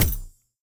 etfx_explosion_sparkle2.wav